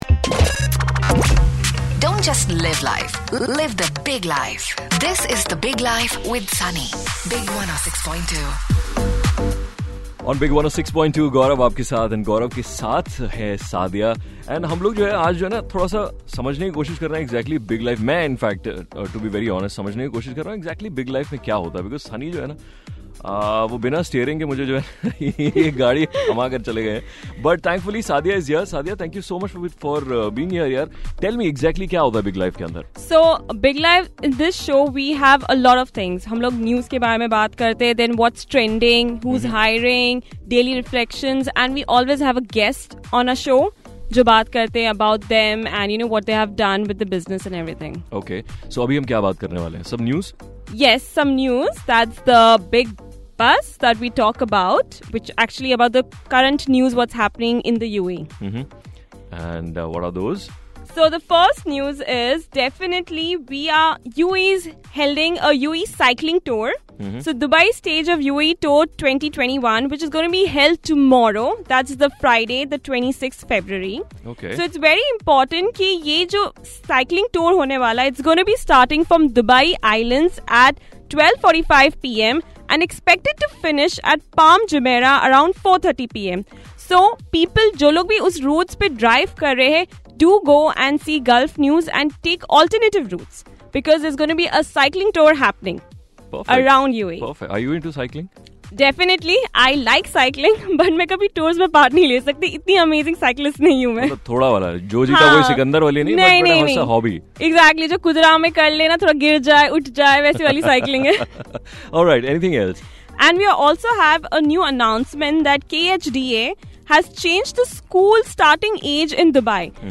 Interview on Decluttering